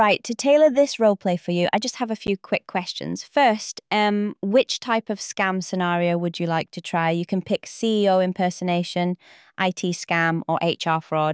She’s calm. She’s professional. She’s scarily convincing... because that’s exactly how real attackers sound.
She sounds real... because she’s built to feel real.
No awkward gaps. No robot energy.